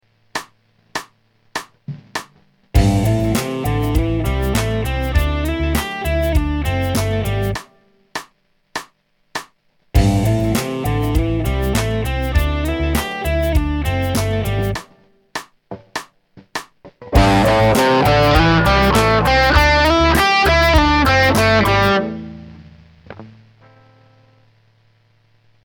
今回の録音から、原曲に重ねてプレイしております☆
Aマイナー・ペンタの拡張ポジション、ということで、横へのポジション移動が多いフレーズです。
U_practice_02.mp3